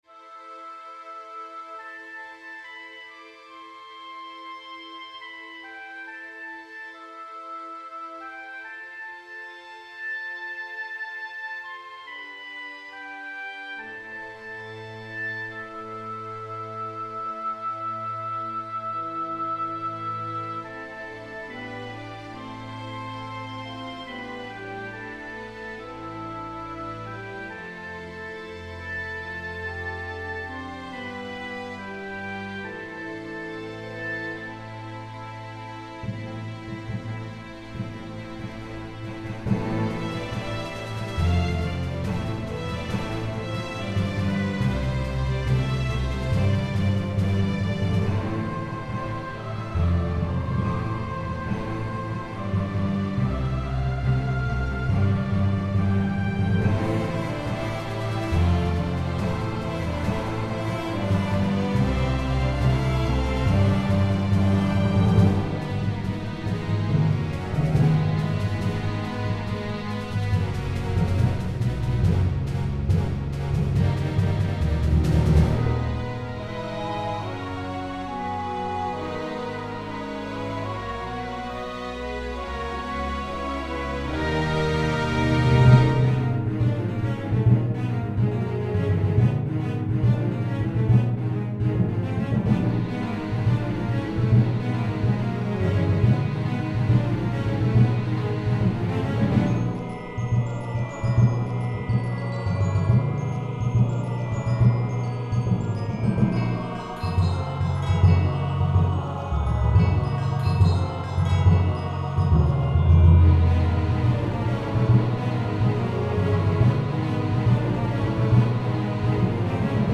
Robotic war Human Theme: A more movie like robotic war, I can get into that.
I just wanted more of those robotic effects though.
The melody isn't going anywhere.
Technique: Orchestral mastery 16/20